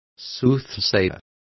Complete with pronunciation of the translation of soothsayer.